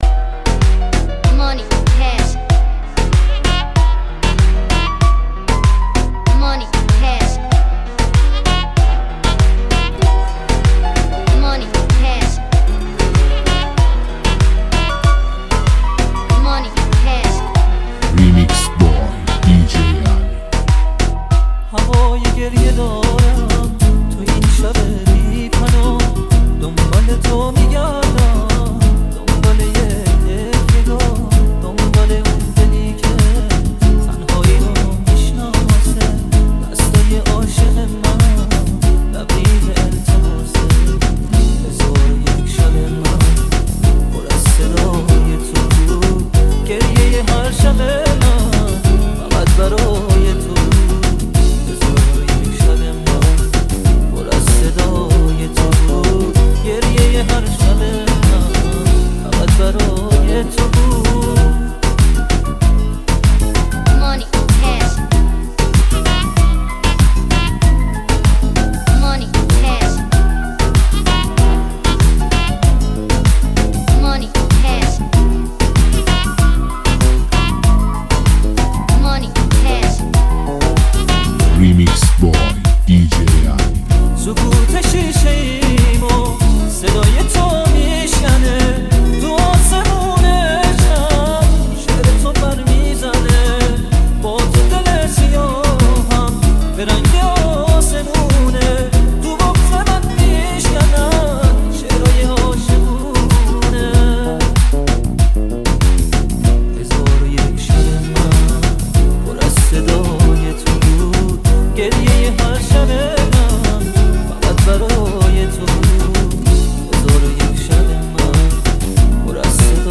دانلود آهنگ و لذت بردن از ریتم‌های جذاب و ملودی‌های دلنشین.